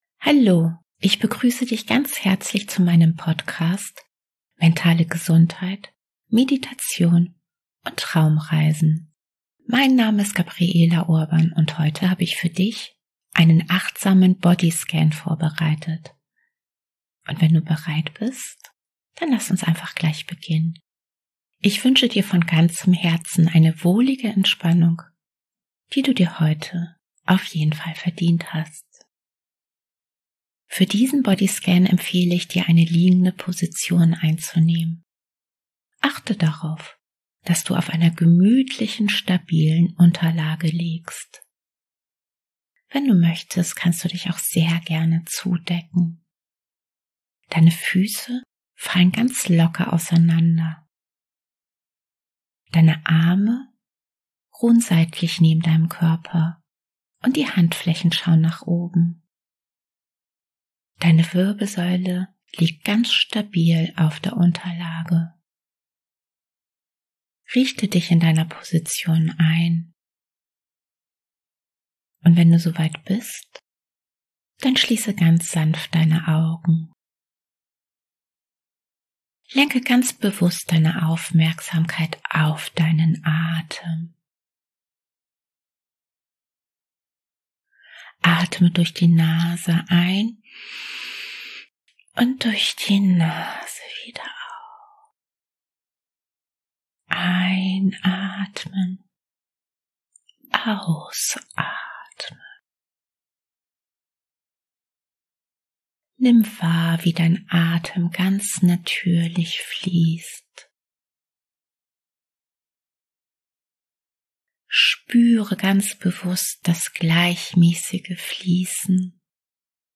Und daher beginnen wir dieses Jahr gemeinsam mit einem entspannten Bodyscan ohne Musik. Dieser pure Bodyscan ohne Musik kann dich dabei unterstützen, deine innere Balance zu finden und zu wahren.